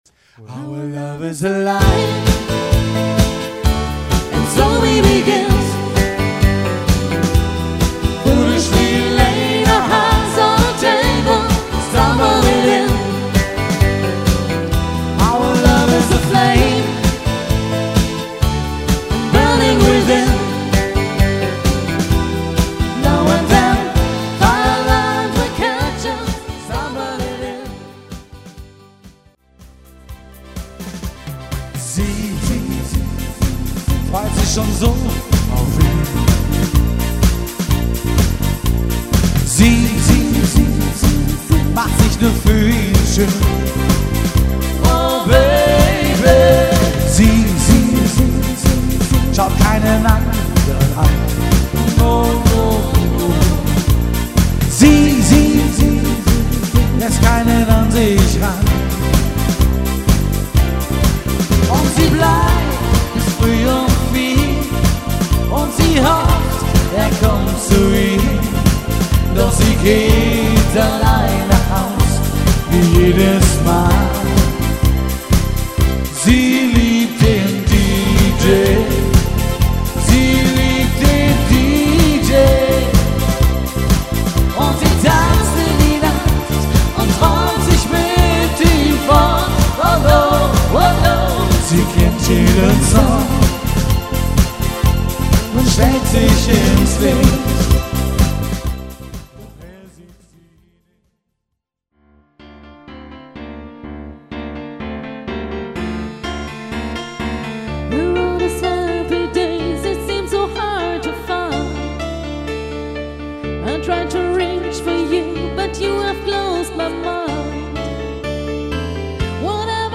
LIVE-Mitschnitte 2010